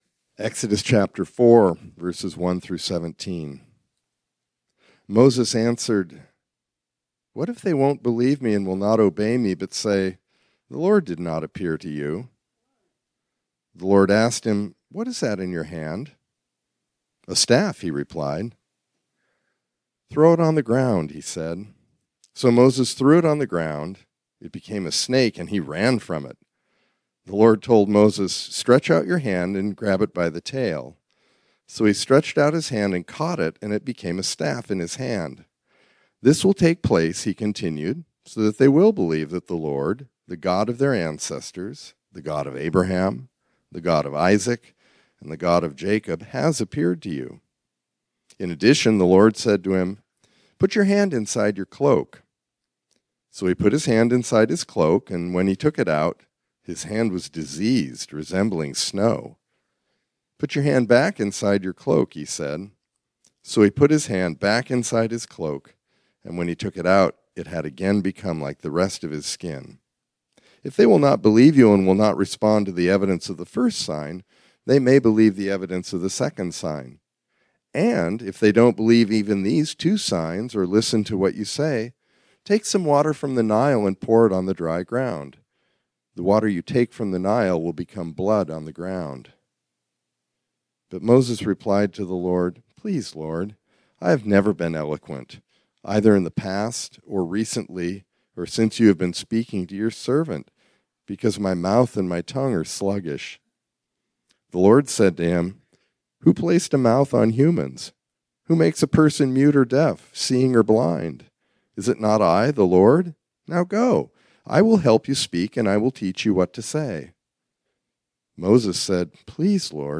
This sermon was originally preached on Sunday, January 26, 2025.